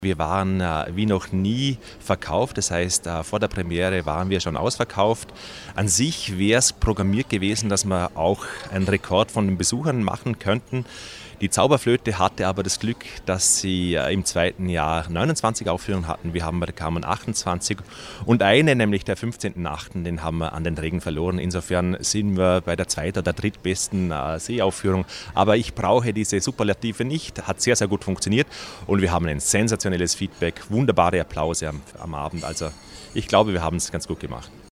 Pressekonferenz Vorläufige Bilanz - News